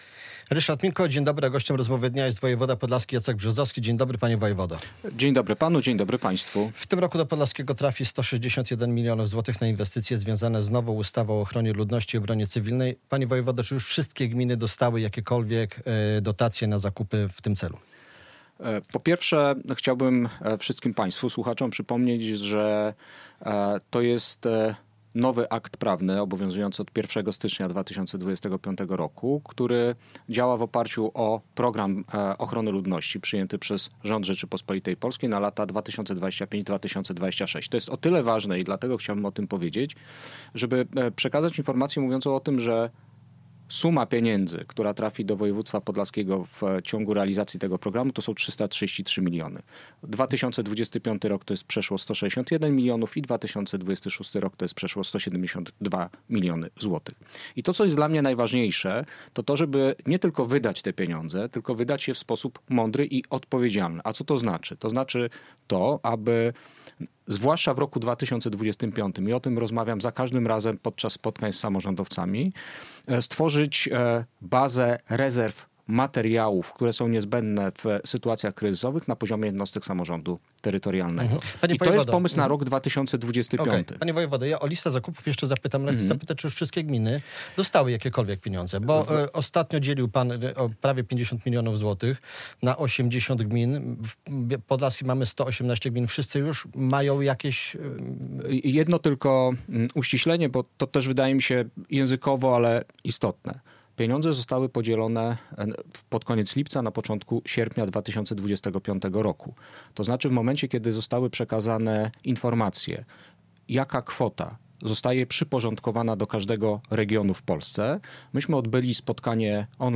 Radio Białystok | Gość | Jacek Brzozowski - wojewoda podlaski